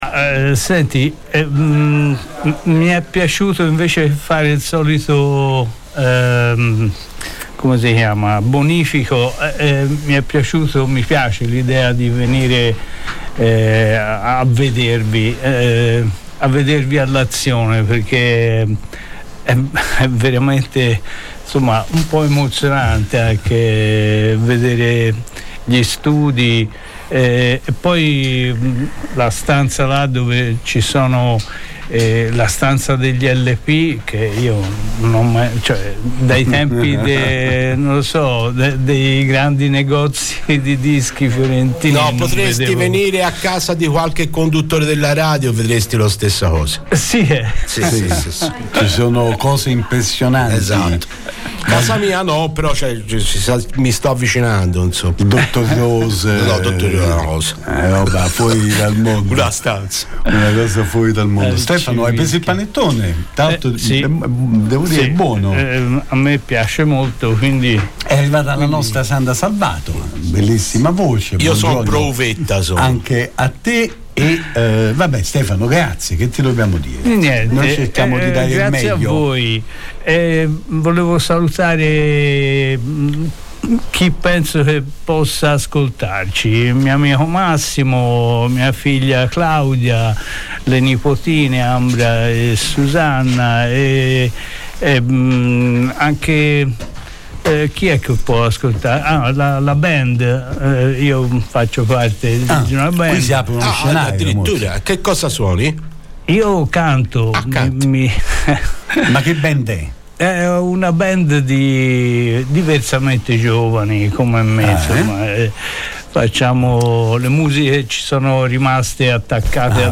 Vendo compro e scambio in diretta su Controradio